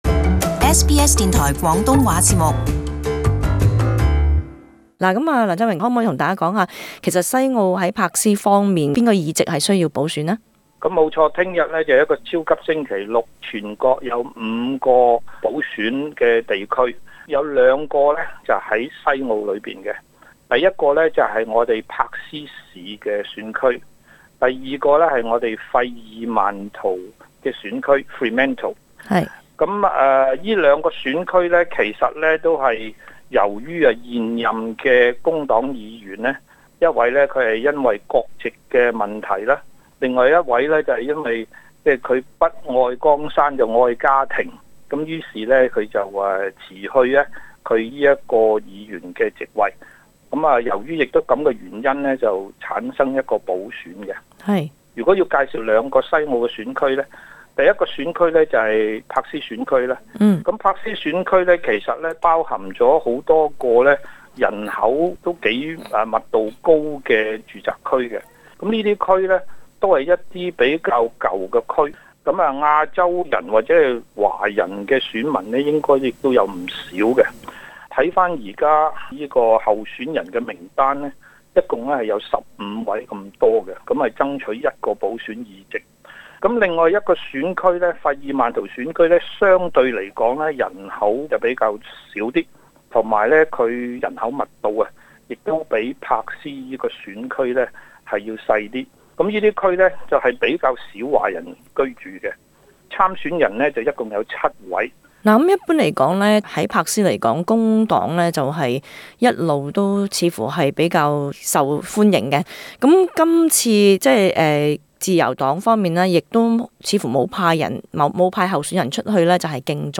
【時事專訪】超級星期六西澳議席補選